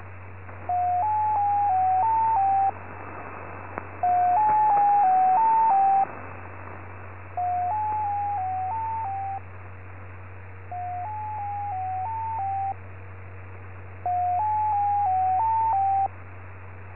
• Mazielka (X06) is a diplomatic selcall system used by the Russian Ministry of Foreign Affairs. Used to alert that a Serdolik transmission will occur soon, usually on a different frequency:
6TONE.mp3